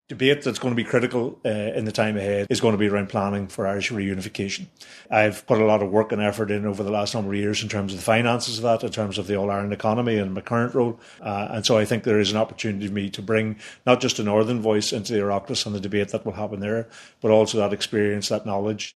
Conor Murphy outlines his priorities: